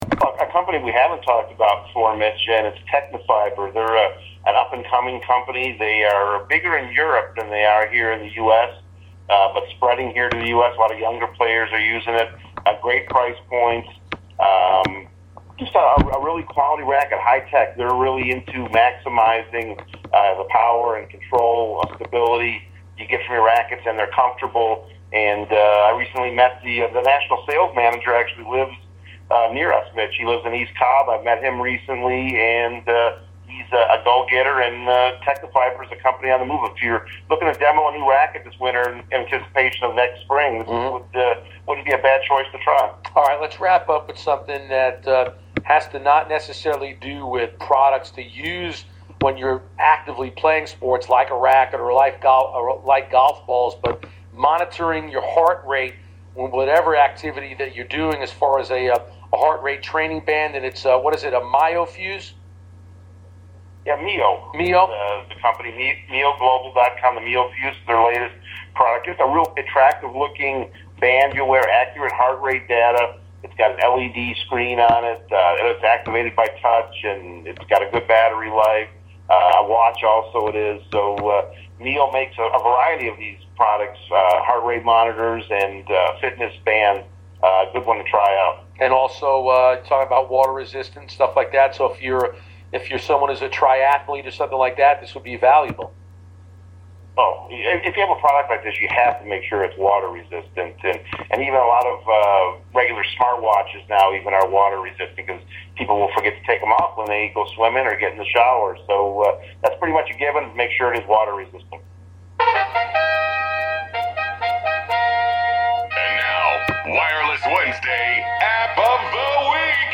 Wireless Wednesday for 12/17/14 on 92.9 The Game Part 2